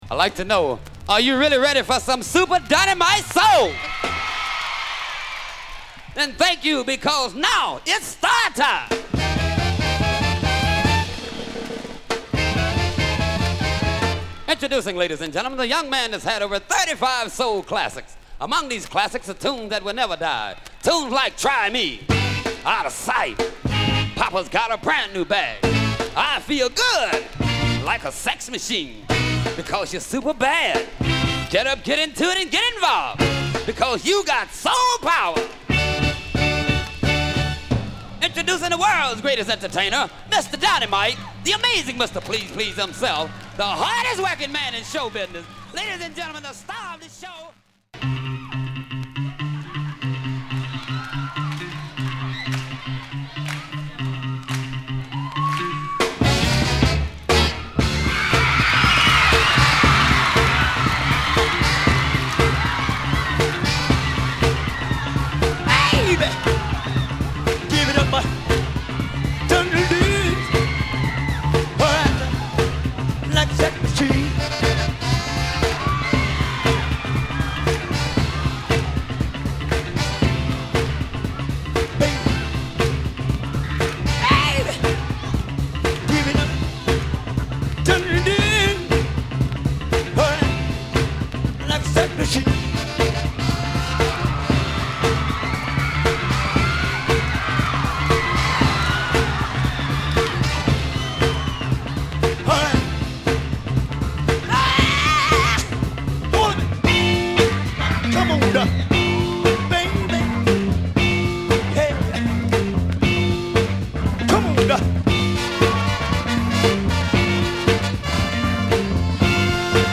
ニューヨークはハーレムの名門『Apollo Theatar』での白熱のライブを収録した71年の名盤！